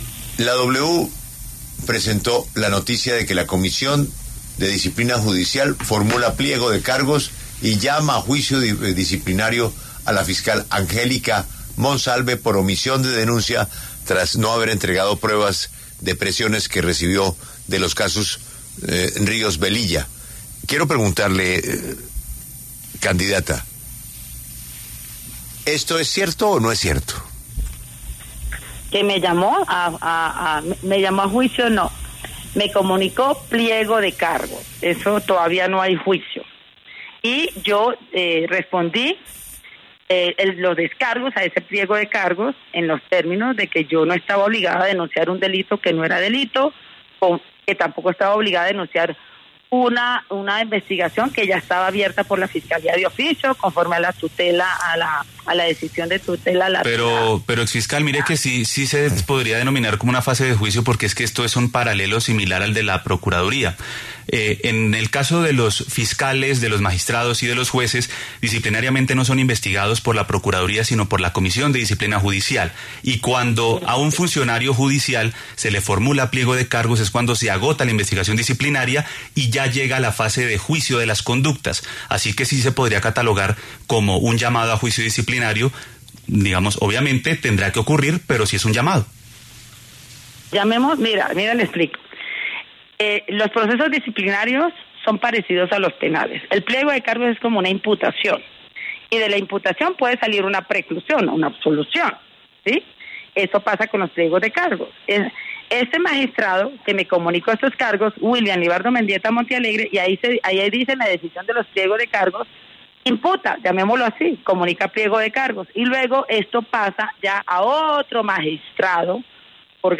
En entrevista con La W, la exfiscal y precandidata presidencial, señaló que no cuenta con pruebas físicas frente a sus denuncias de presiones en su contra.